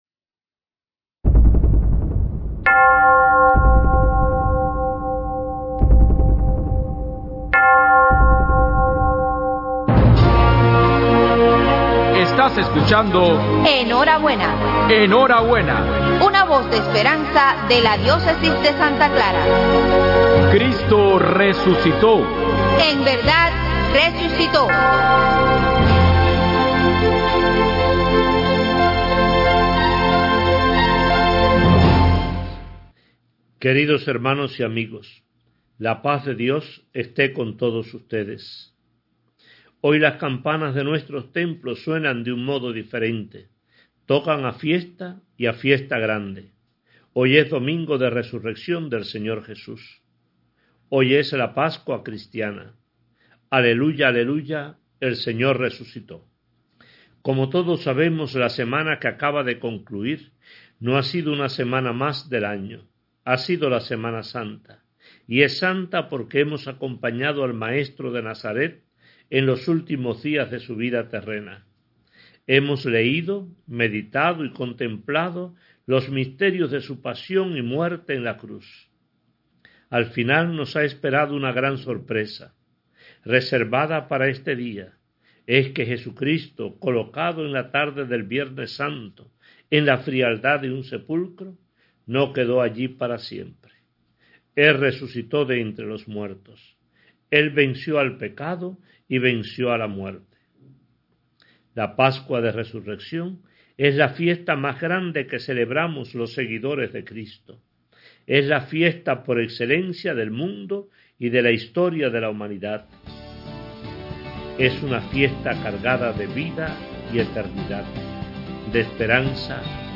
DOMINGO DE RESURRECCIÓN: MENSAJE RADIAL DE MONS. ARTURO GONZÁLEZ AMADOR, OBISPO DE SANTA CLARA - Conferencia de Obispos Católicos de Cuba